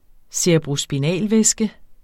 Udtale [ seɐ̯əbʁosbiˈnæˀl- ]